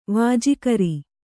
♪ vāji kari